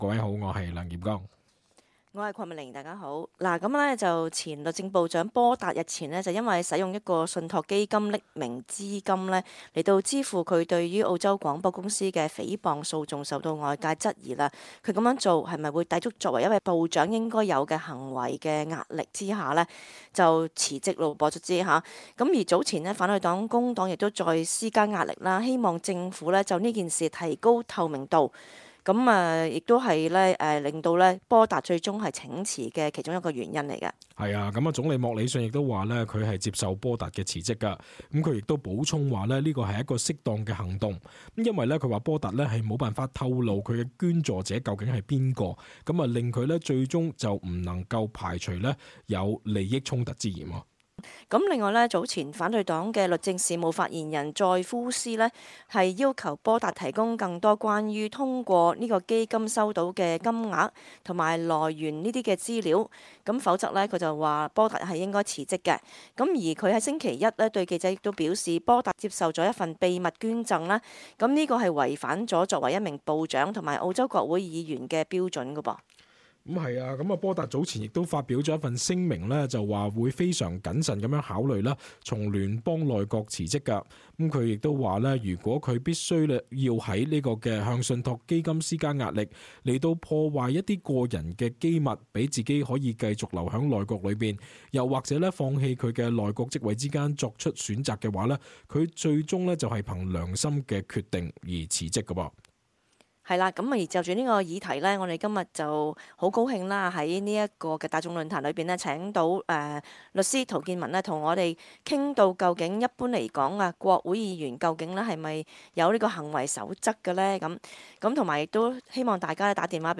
cantonese_-talkback_-_sept_23.mp3